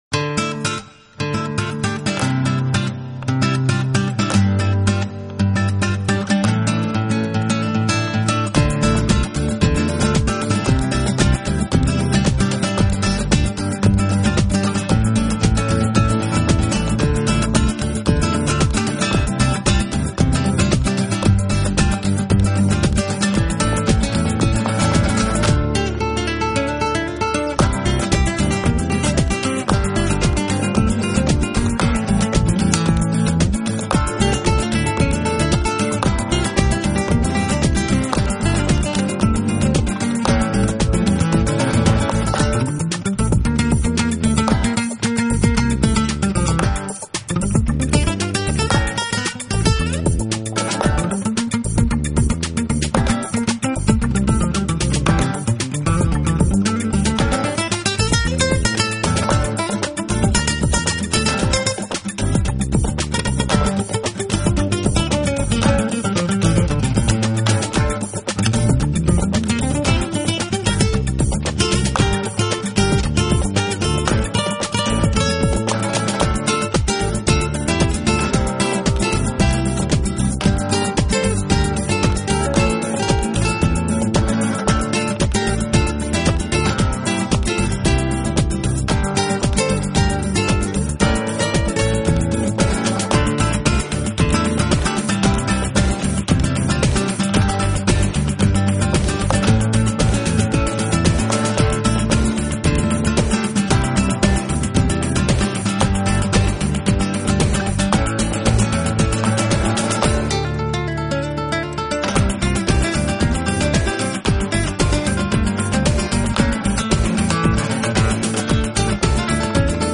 弗拉门戈吉他